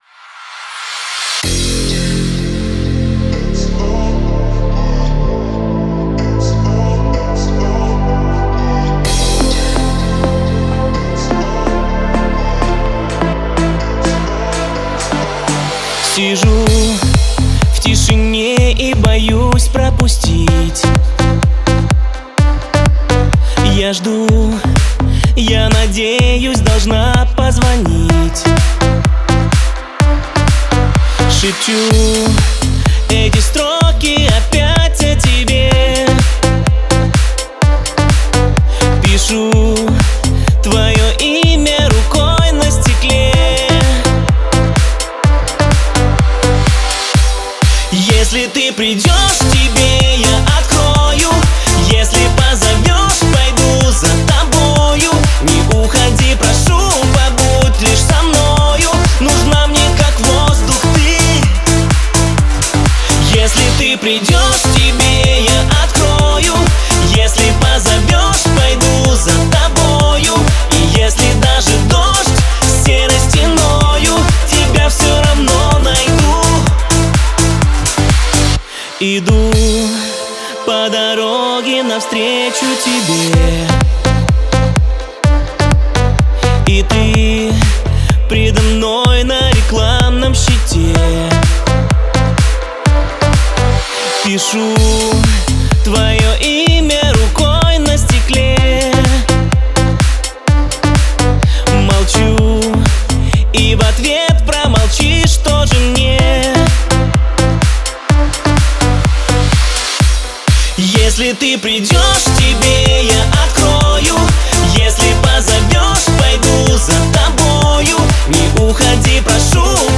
электронная композиция